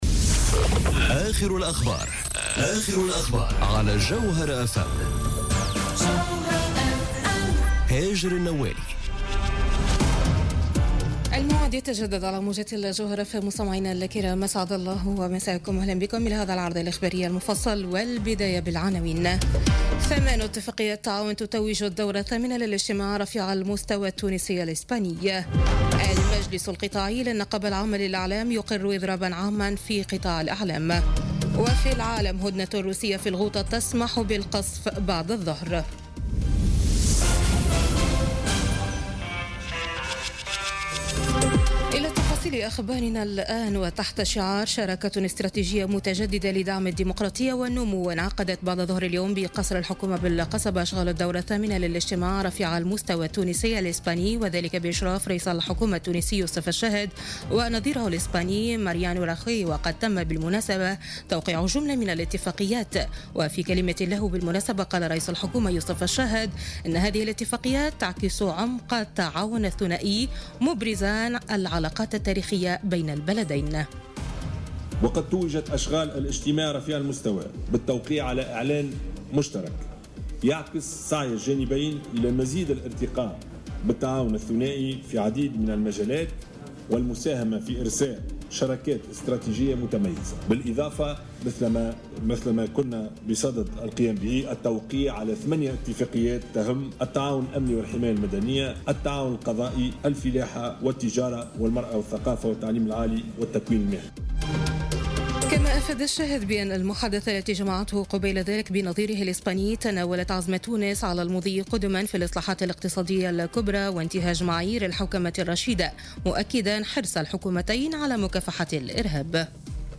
نشرة أخبار السابعة مساء ليوم الاثنين 26 فيفري 2018